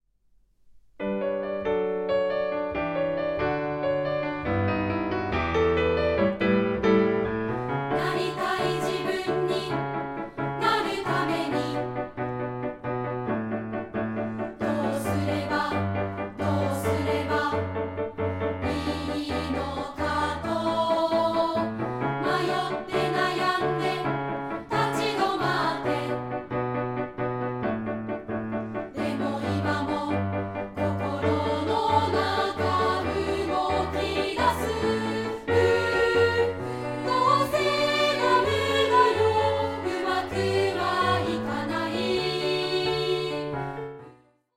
同声2部合唱／伴奏：ピアノ